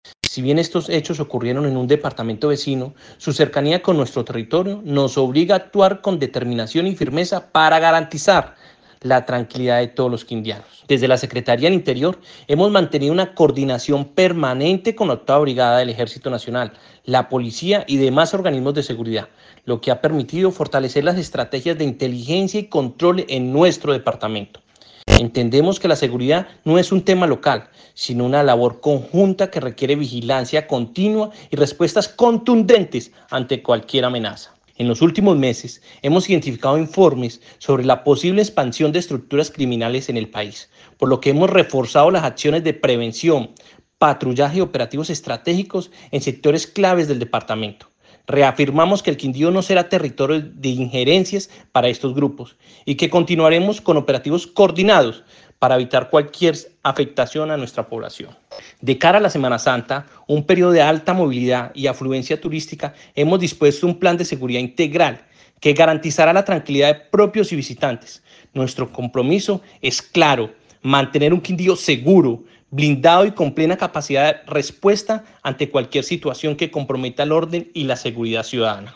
Secretario del Interior del Quindío, Jaime Andrés Pérez